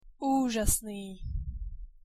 pronunciation_sk_uzasny.mp3